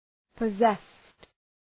Προφορά
{pə’zest}